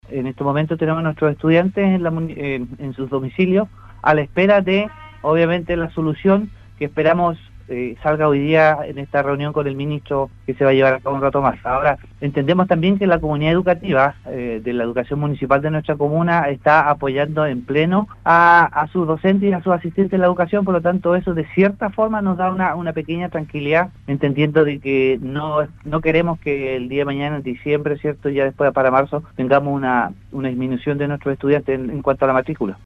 El concejal Rubén González, señaló que los alumnos de la educación municipal se encuentran aún sin clases, a la espera de la reunión con el ministro de Educación.